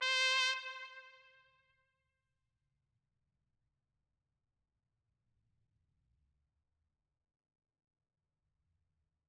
Trumpet - Mariachi Trumpet.wav